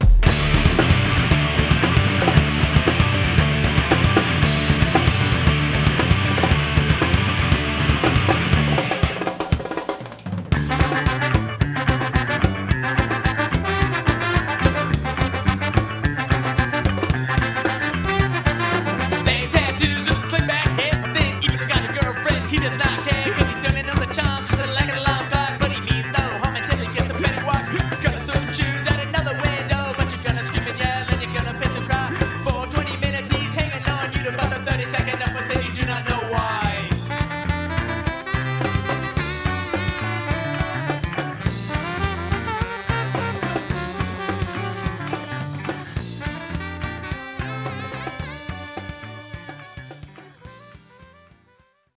Sacramento Sub Urban Ska Core from: